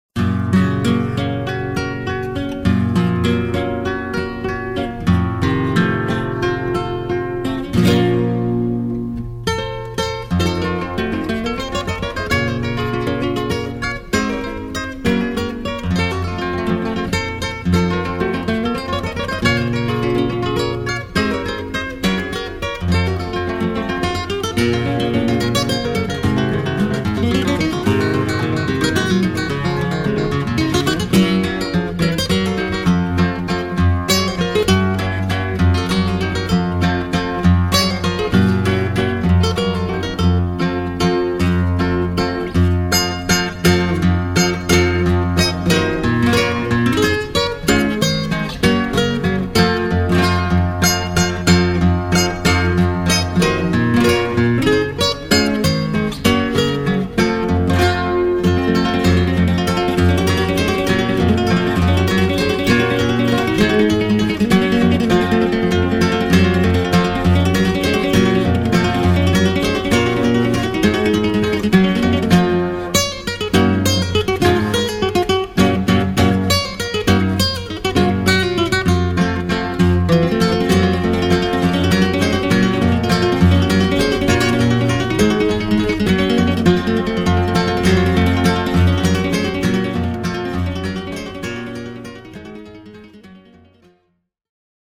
guitarrista